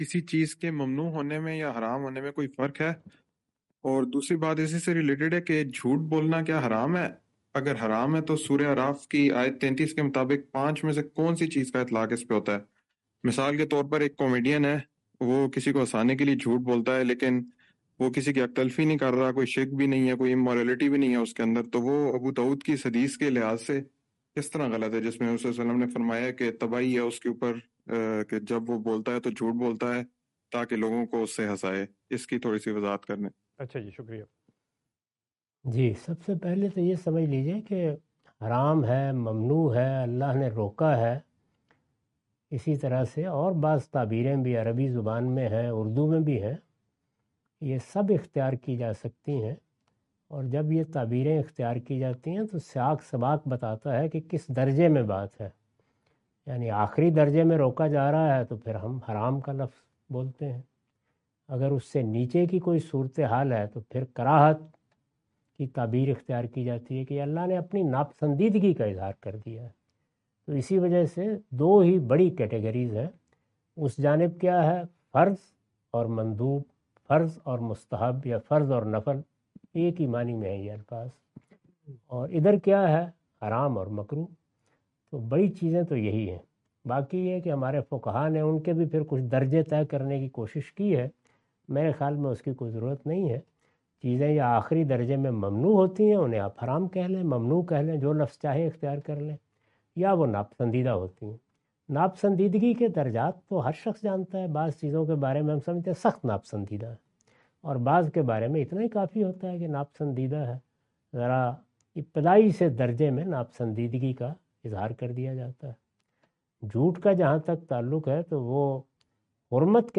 Category: Reflections / Questions_Answers /
In this video, Mr Ghamidi answer the question about "What is the difference between prohibited and haram?".